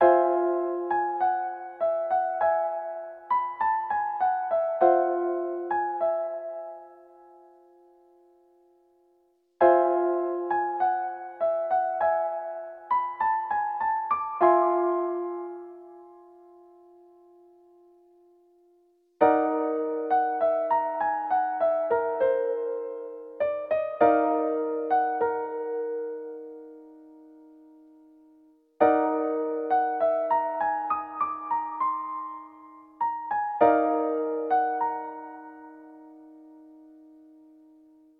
クラッシックギターとフルートで優しく奏でる曲です。...